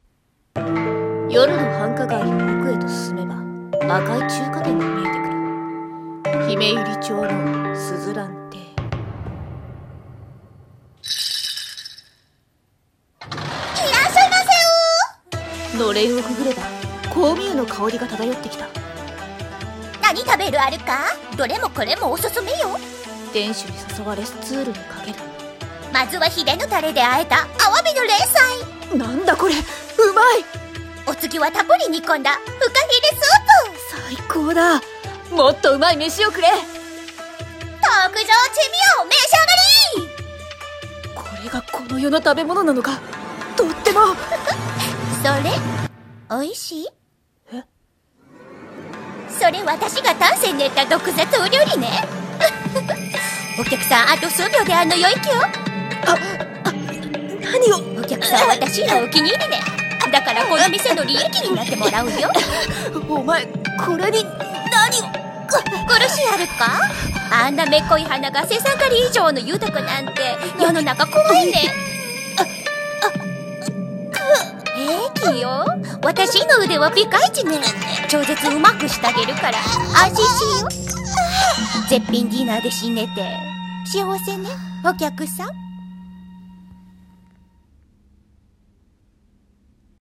声劇】珍味の鈴蘭中華店